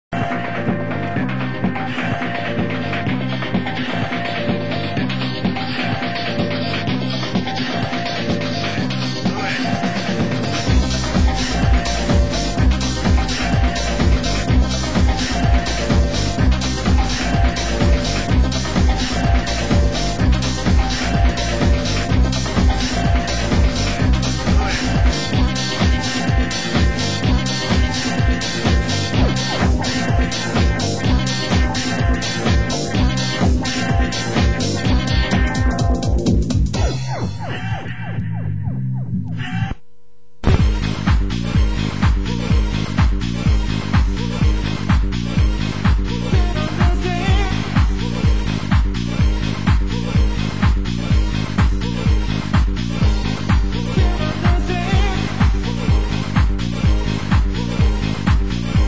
Format: Vinyl 12 Inch
Genre: US House